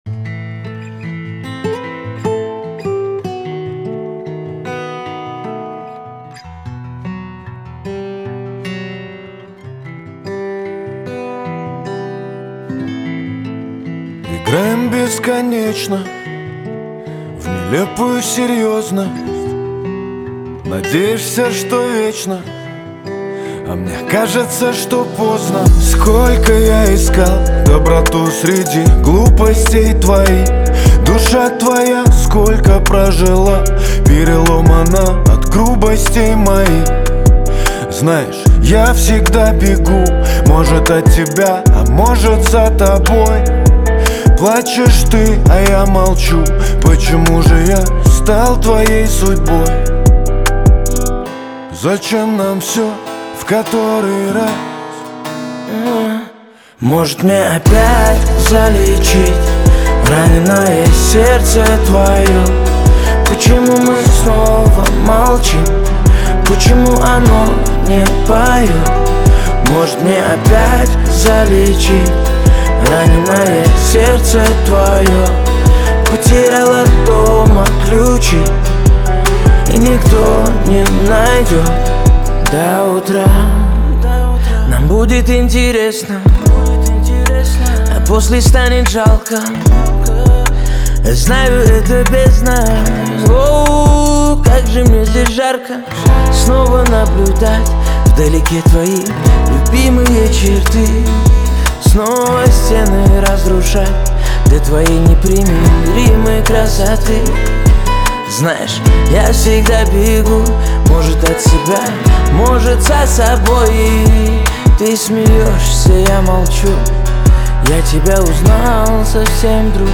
Качество: 320 kbps, stereo
Pop